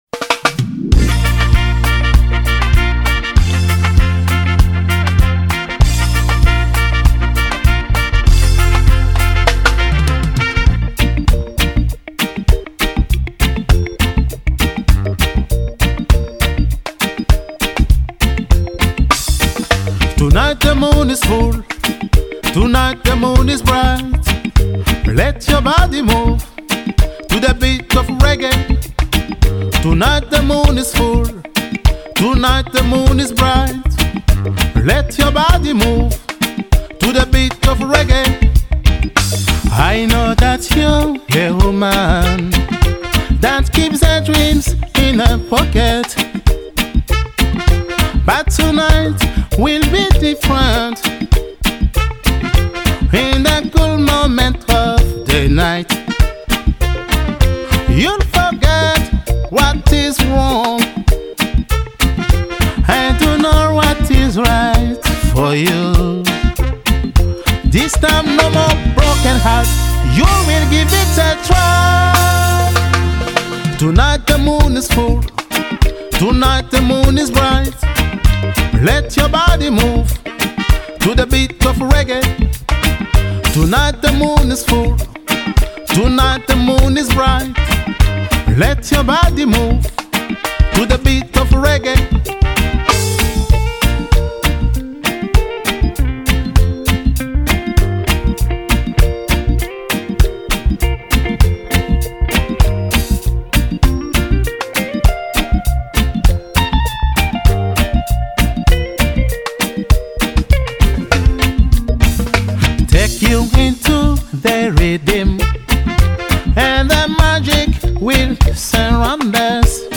a été enregistré dans les conditions d’un live.